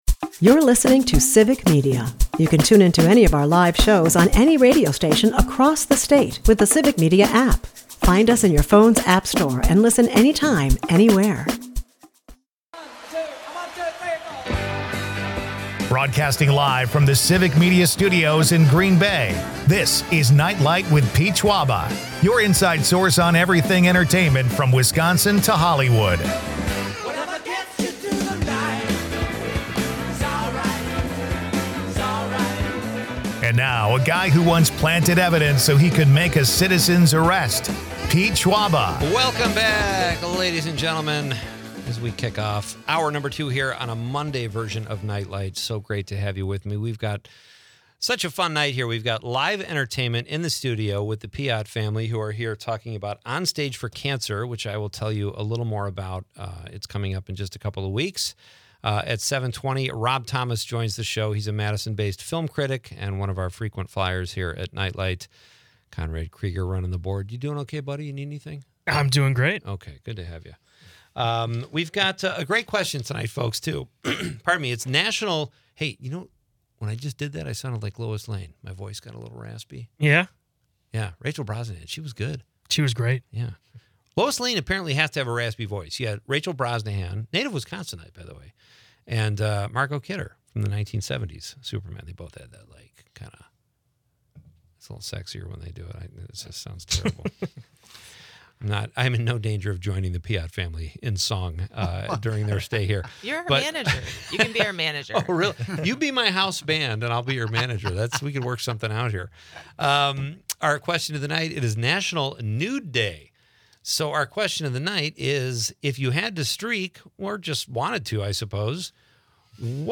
A lively mix of music, movies, and mischief makes for a dynamic episode of entertainment and humor.